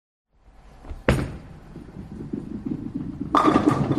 Bowling Strike
087_bowling_strike.mp3